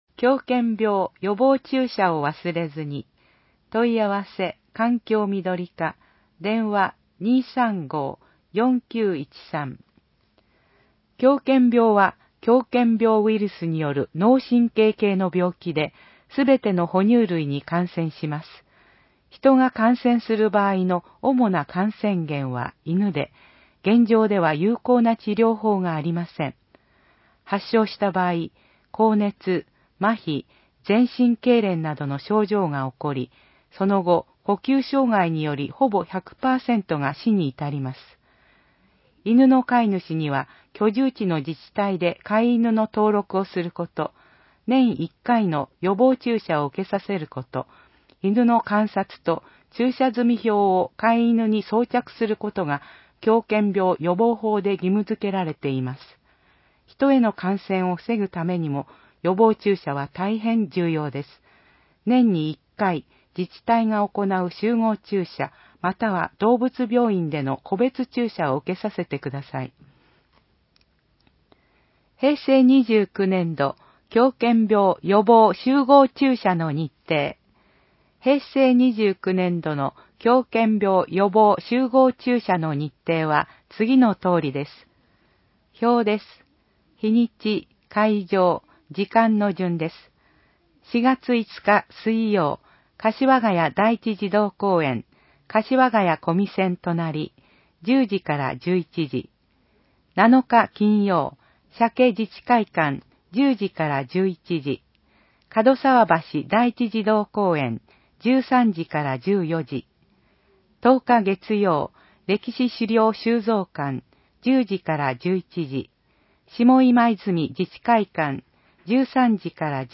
広報えびな 平成29年3月15日号（電子ブック） （外部リンク） PDF・音声版 ※音声版は、音声訳ボランティア「矢ぐるまの会」の協力により、同会が視覚障がい者の方のために作成したものを登載しています。